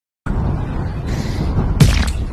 Plarp_seal.mp3